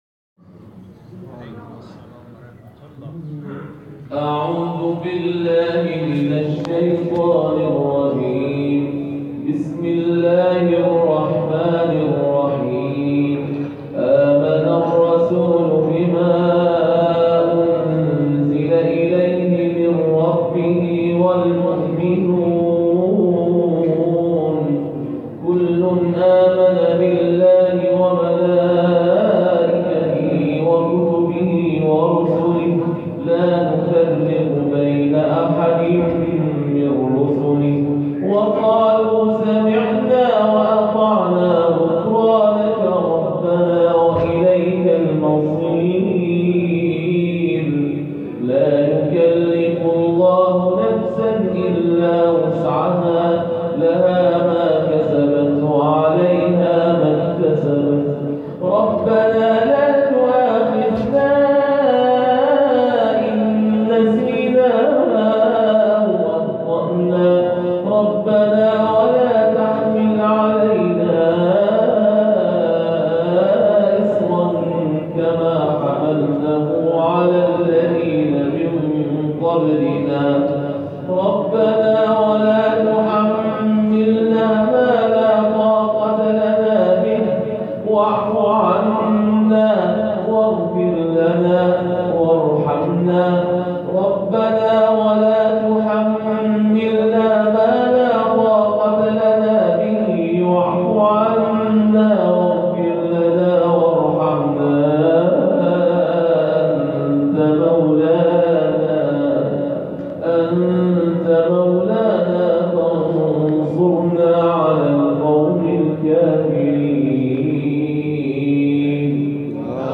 جدیدترین تلاوت ترتیل